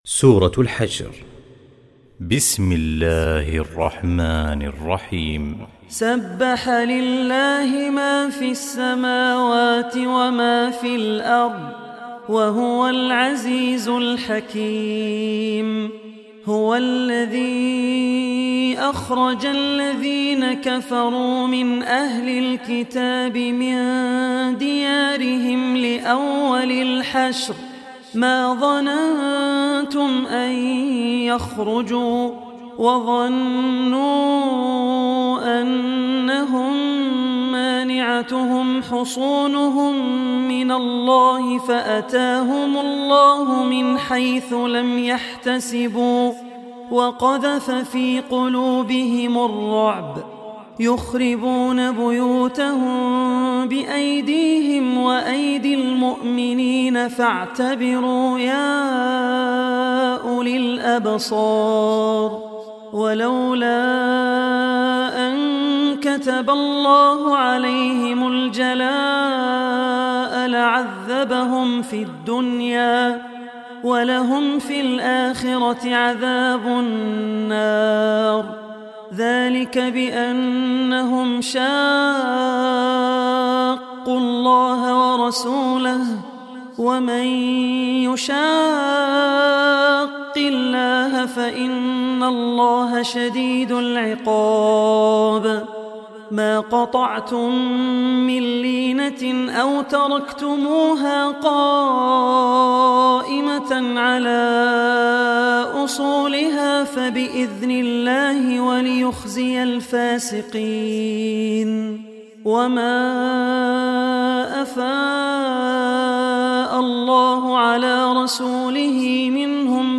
Surah Al-Hashr Recitation by Abdur Rehman Al Ossi
Surah Al-Hashr is 59 chapter or Surah of Holy Quran. Listen online mp3 tilawat / recitation in the beautiful voice of Abdur Rehman Al Ossi.